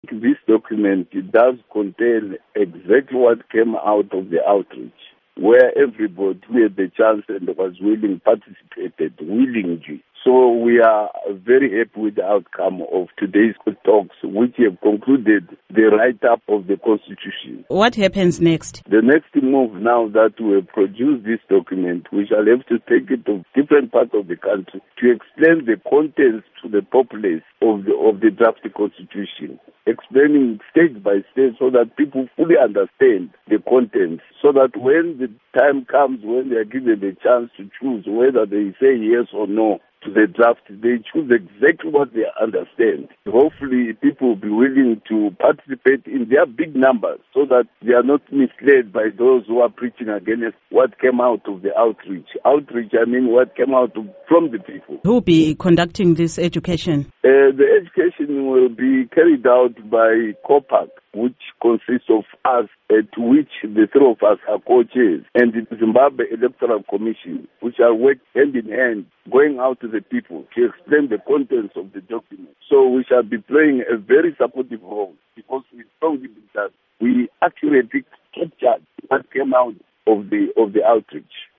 Interview with Edward Mkosi